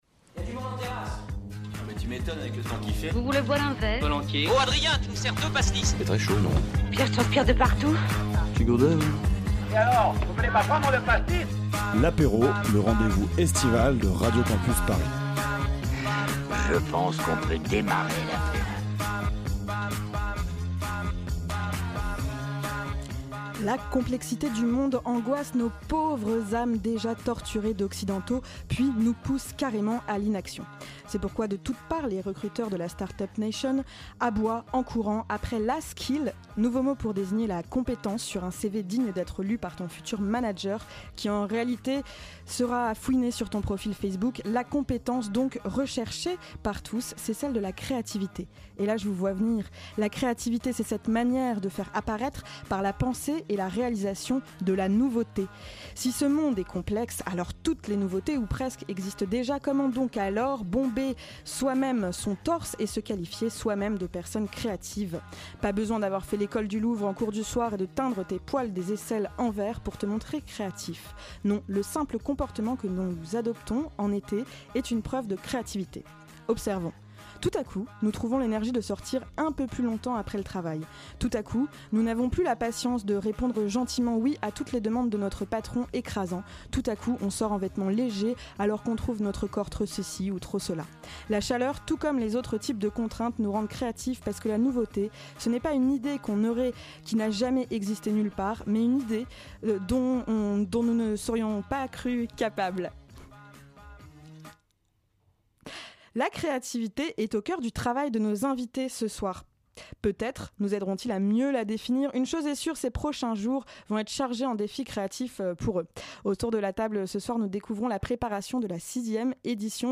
Autour de notre table ce soir, nous découvrons la préparation de la sixième édition du festival Acte et Fac.